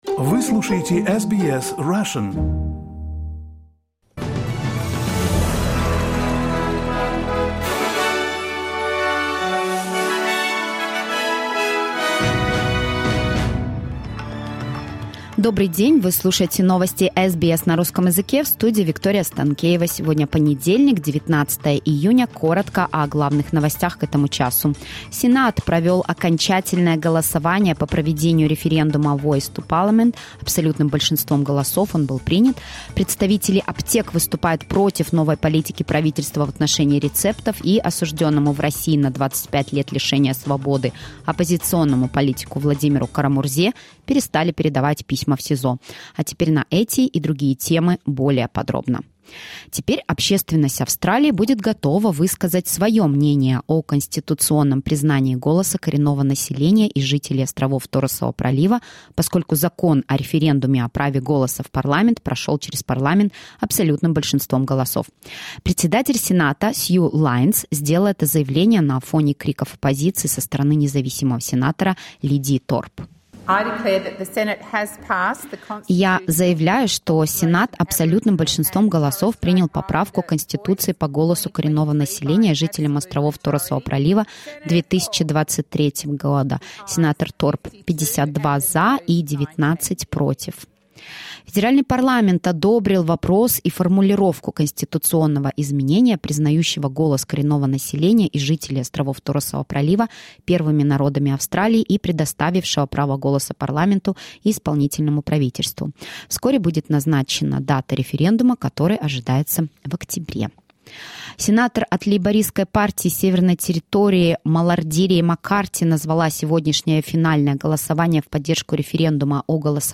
SBS news in Russian — 19.06.2023